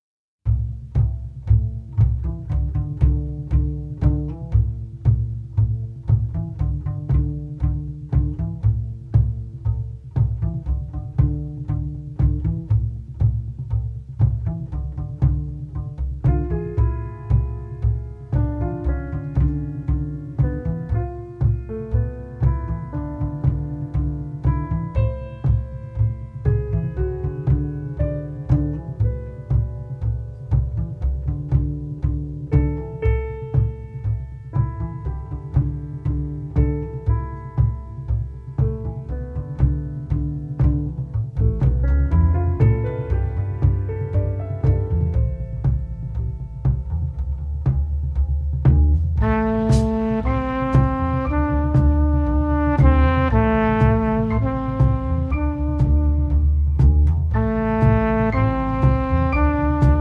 trumpet
pianist
bassist
drummer